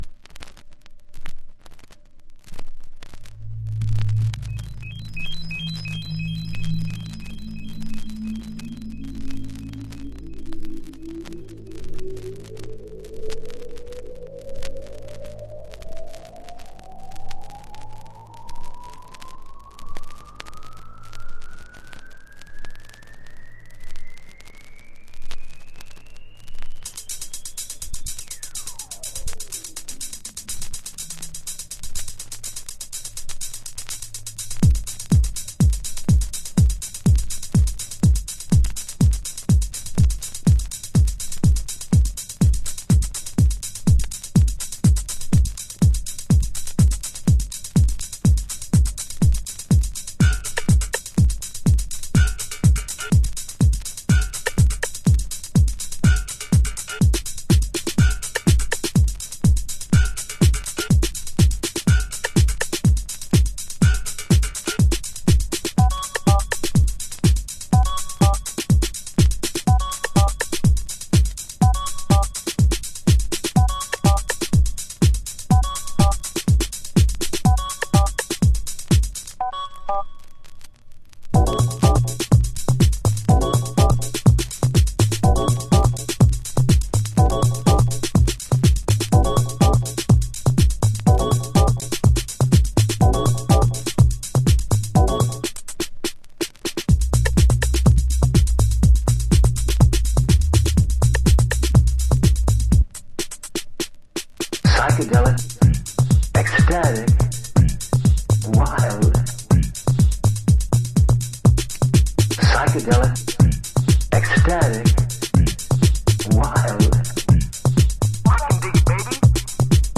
そのまんまサイケデリックなブリープテクノ快作。
House / Techno